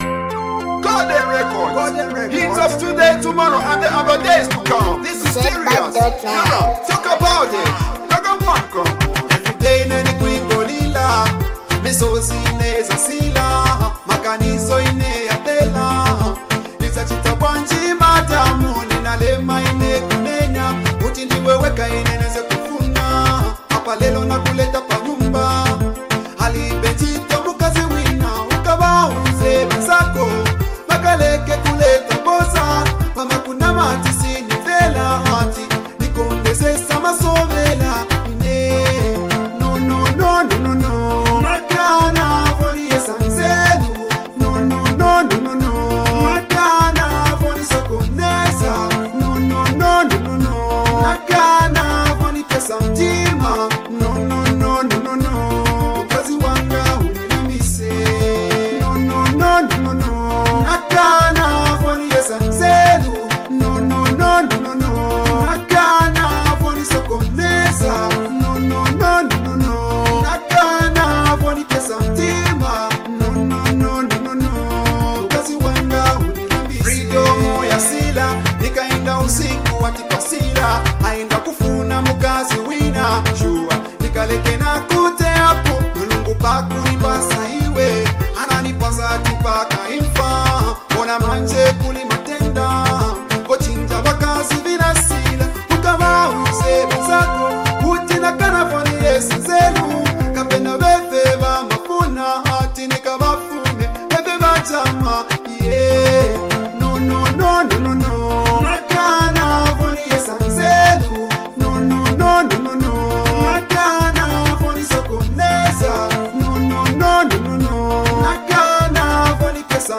The beat is fast, and the instruments sound amazing.